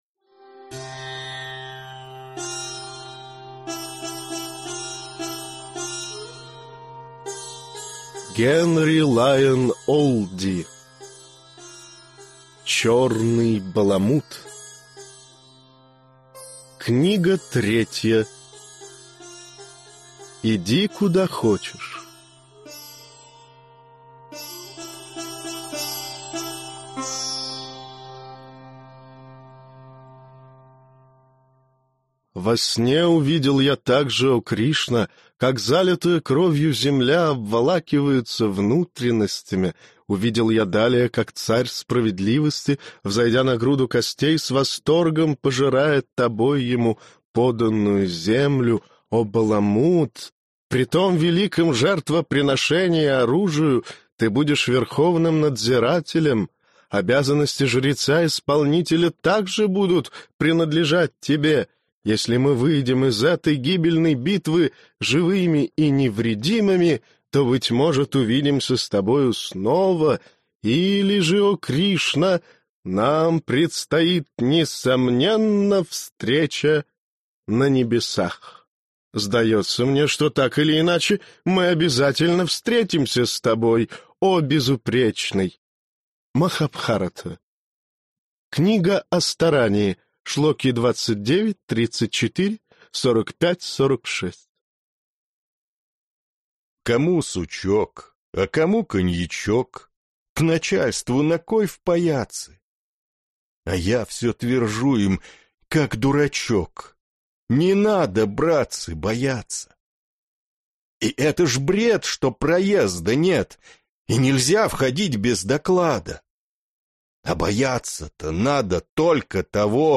Аудиокнига Иди куда хочешь